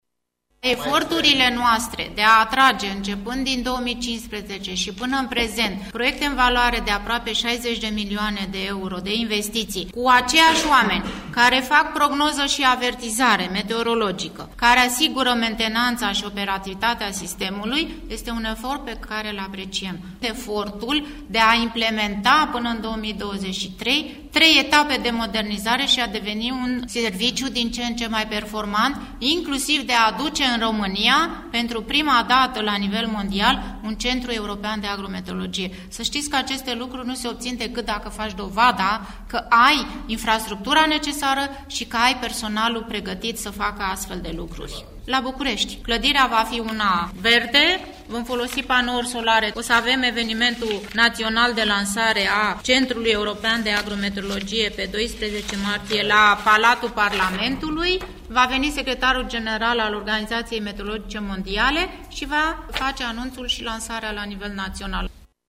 Centrul European de Agro-Meteorologie va funcționa, de luna viitoare, în România, la București, a anunțat astăzi, la Oradea, directorul general al Administrației Naționale de Meteorologie Elena Mateescu.